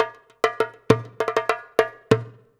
100DJEMB26.wav